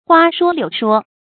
花說柳說 注音： ㄏㄨㄚ ㄕㄨㄛ ㄌㄧㄨˇ ㄕㄨㄛ 讀音讀法： 意思解釋： 形容說虛假而動聽的話哄人。